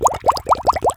Bubbles